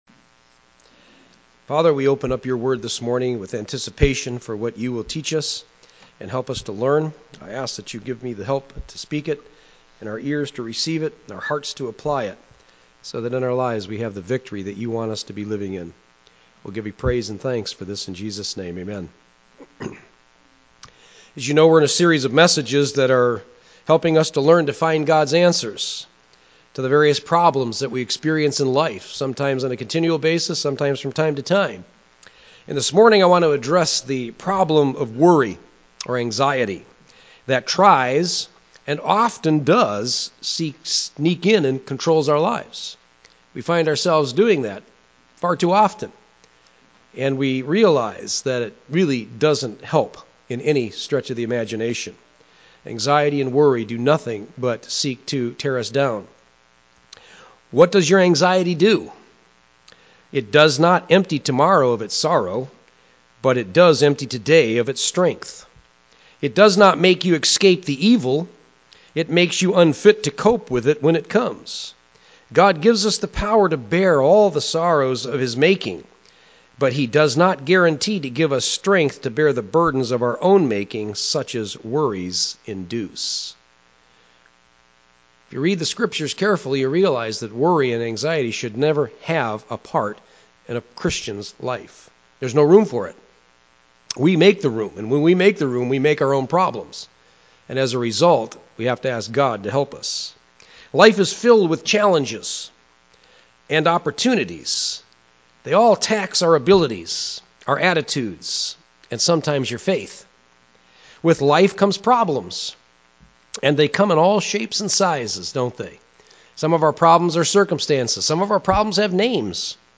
A message from the series "General."
Sermon